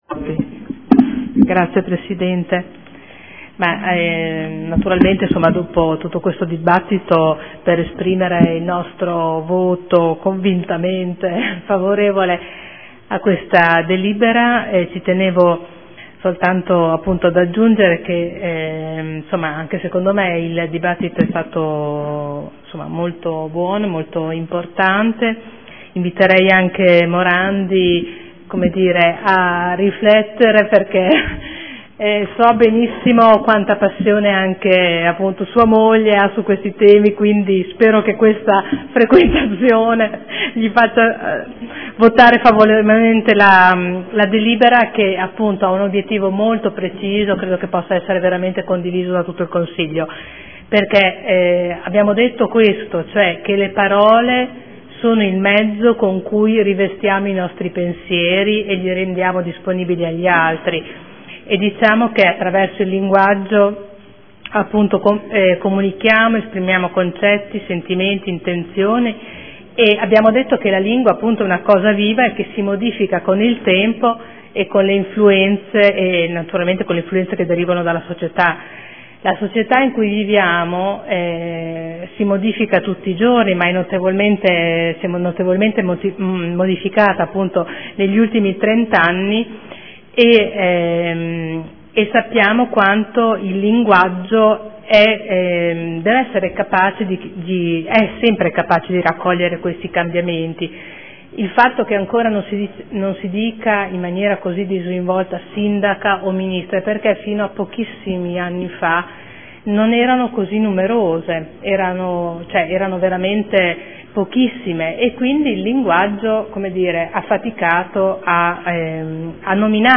Seduta del 05/02/2015 Dichiarazione di voto.